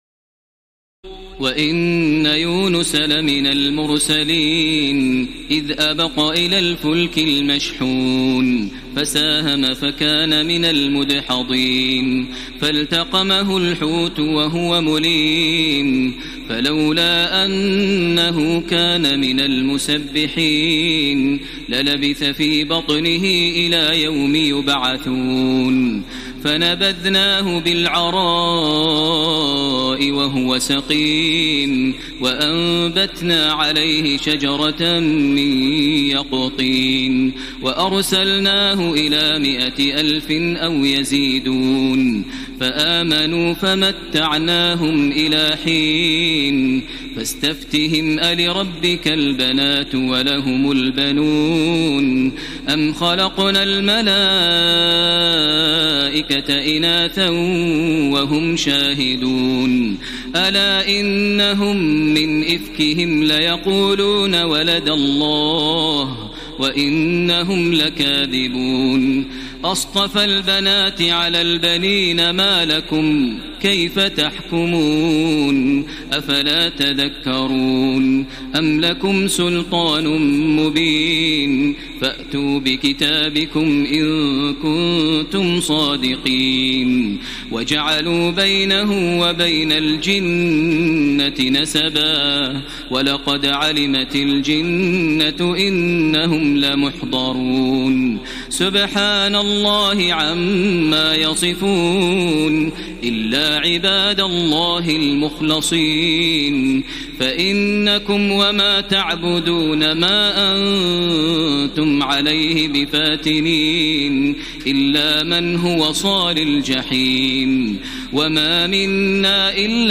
تراويح ليلة 22 رمضان 1433هـ من سور الصافات (139-182) وص و الزمر (1-31) Taraweeh 22 st night Ramadan 1433H from Surah As-Saaffaat and Saad and Az-Zumar > تراويح الحرم المكي عام 1433 🕋 > التراويح - تلاوات الحرمين